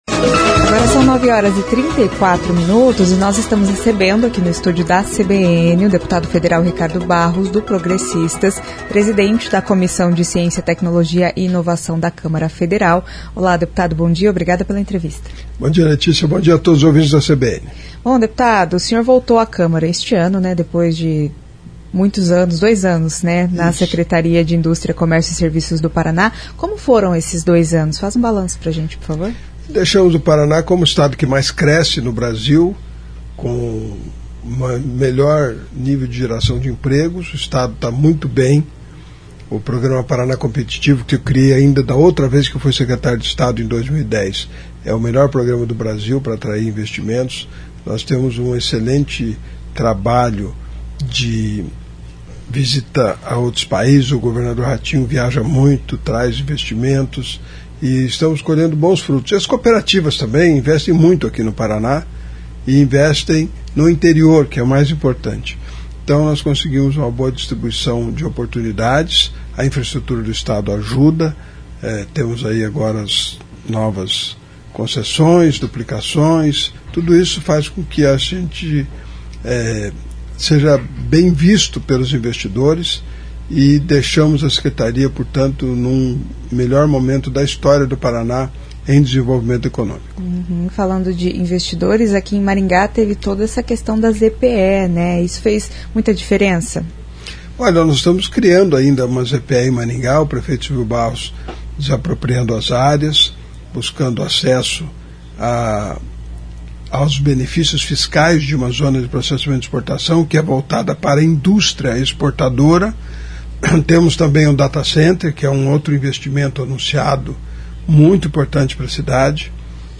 Em entrevista à CBN, o deputado Ricardo Barros falou da intenção de consolidar Maringá como polo de medicamentos.